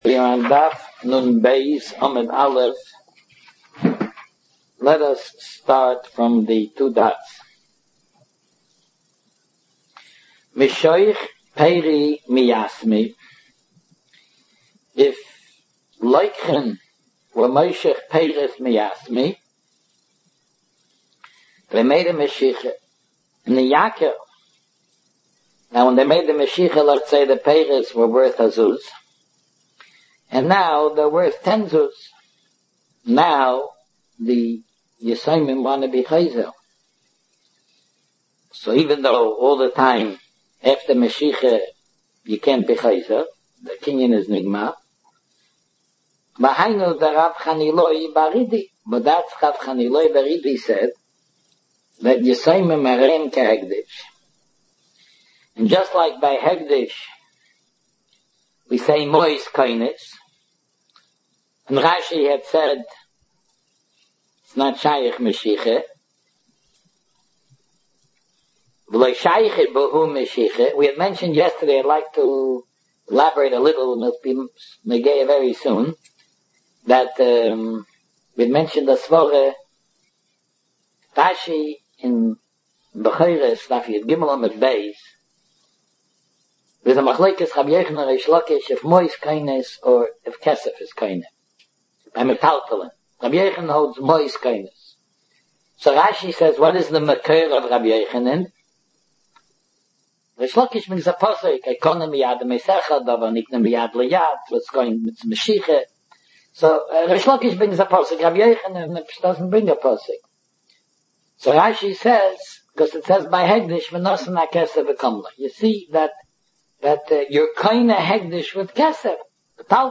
Shiurim, Lectures